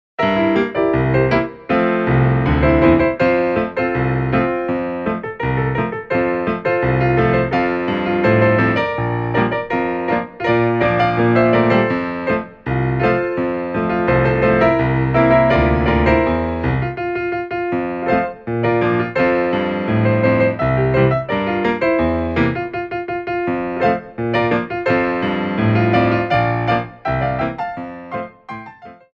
Medium Allegro 1
4/4 (16x8)